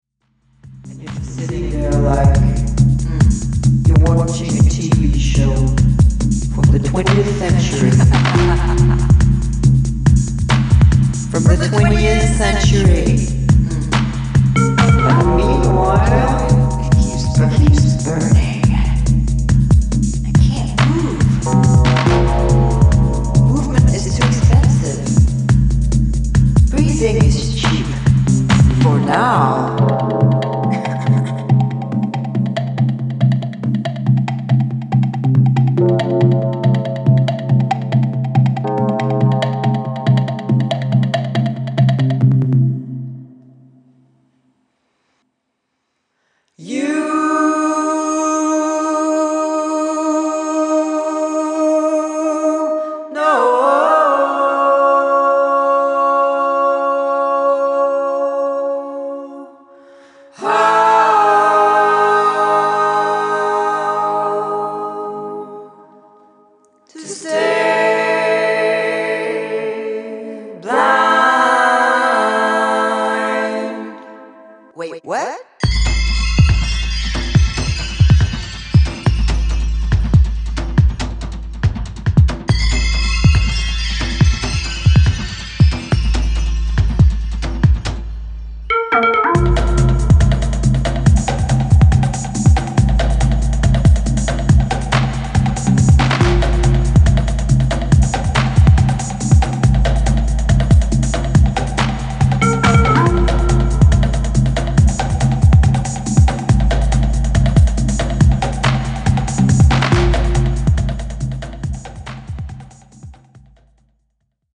with the vocal female discoloop.